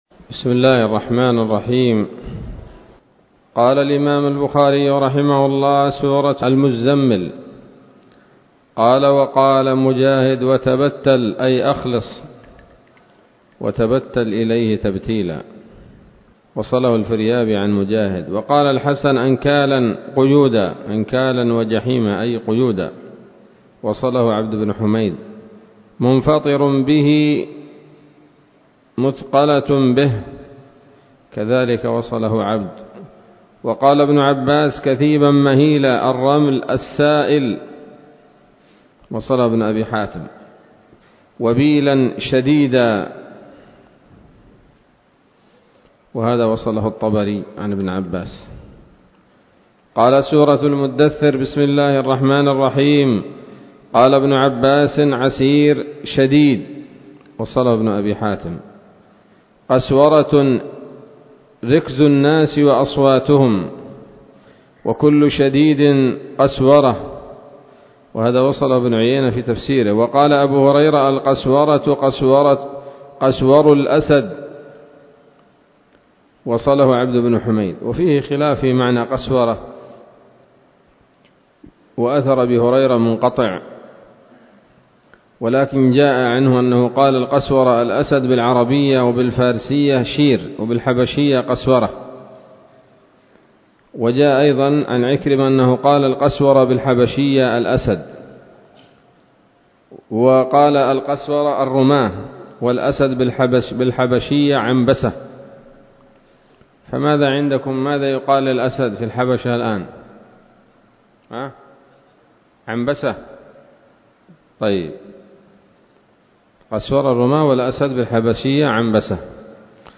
الدرس الخامس والسبعون بعد المائتين من كتاب التفسير من صحيح الإمام البخاري